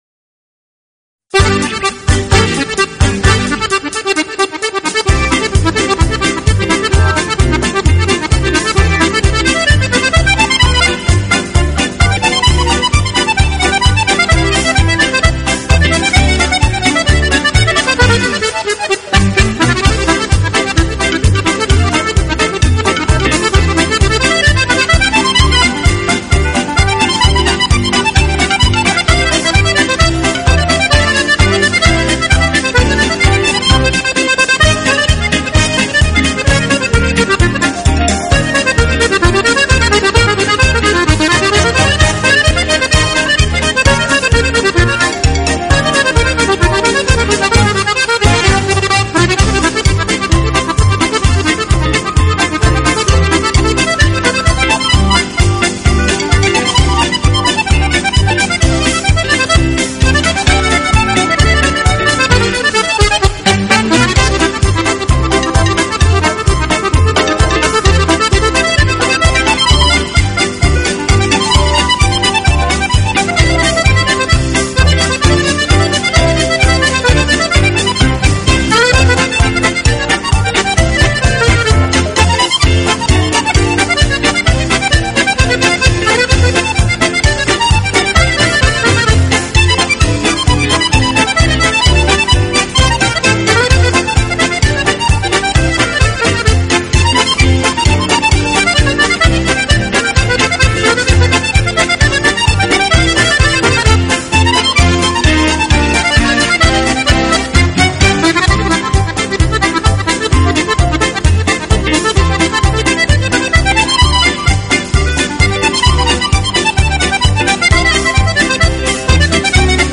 手风琴没有太强冲突，而以旋律流畅欢快优美为特征。